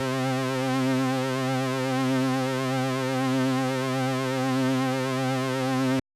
Synths